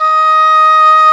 Index of /90_sSampleCDs/Roland L-CDX-03 Disk 1/WND_English Horn/WND_Eng Horn 2